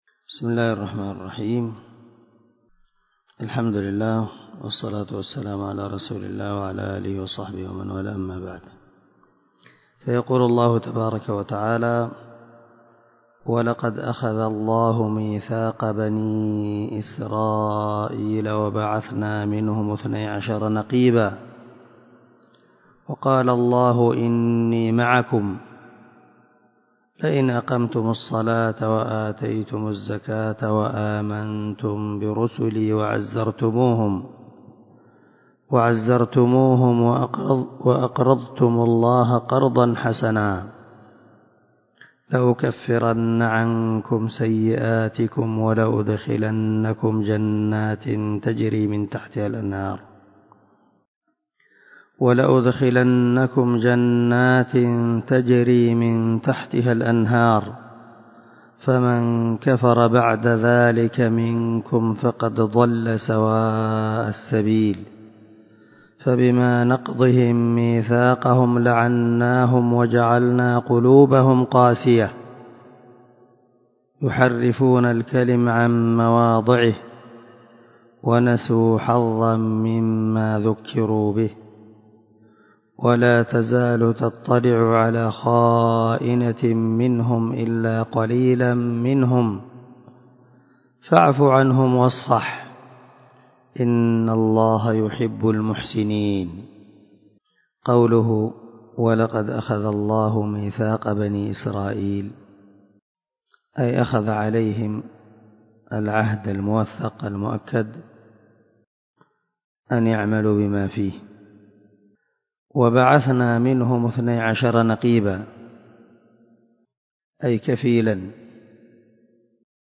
347الدرس 14 تفسير آية ( 12 – 13 ) من سورة المائدة من تفسير القران الكريم مع قراءة لتفسير السعدي